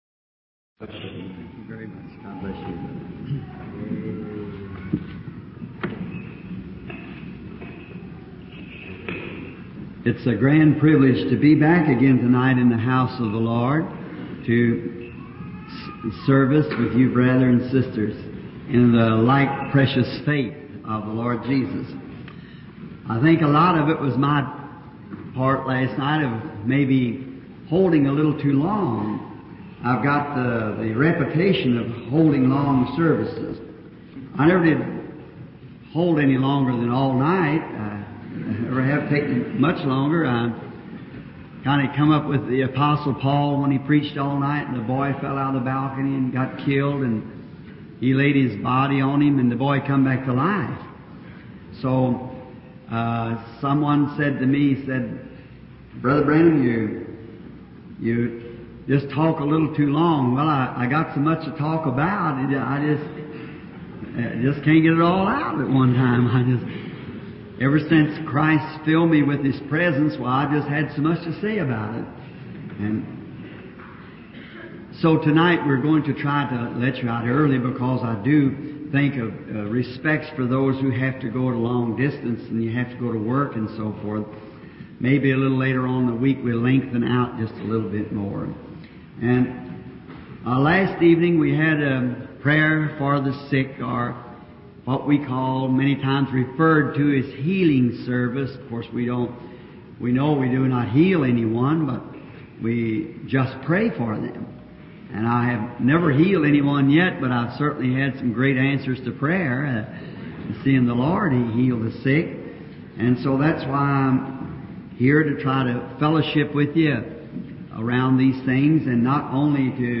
Dieses Portal gibt Ihnen die Möglichkeit, die ca. 1200 aufgezeichneten Predigten von William Marrion Branham aufzurufen, zu lesen oder zu hören.